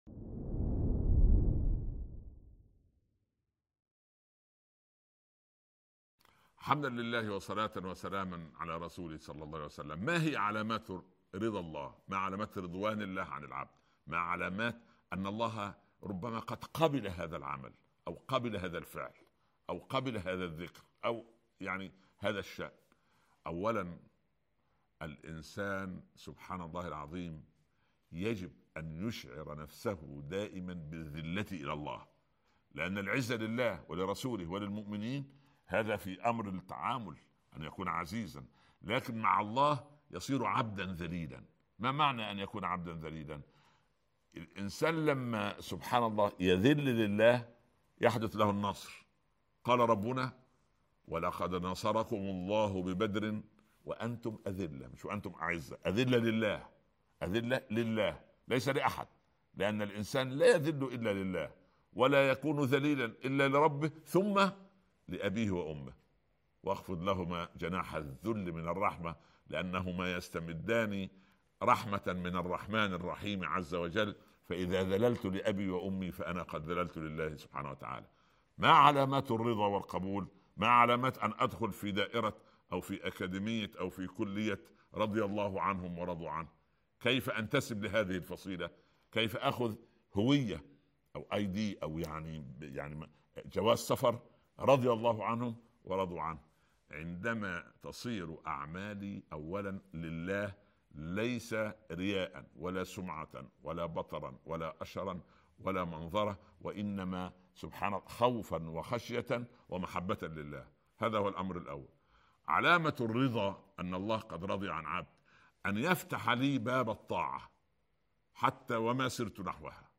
lectures